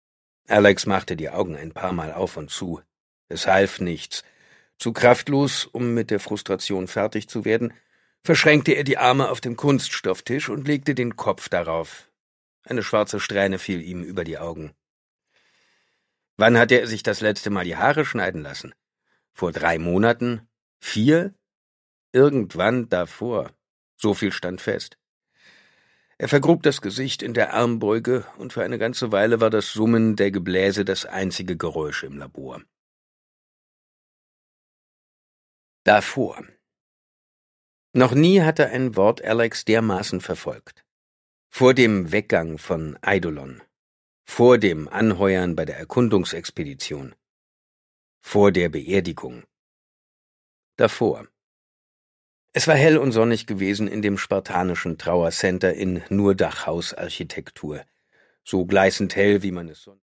Produkttyp: Hörbuch-Download
Gelesen von: Simon Jäger
Simon Jäger macht mit seiner markanten, eindringlichen Stimme diese Space Opera zu einem spannenden Hörerlebnis.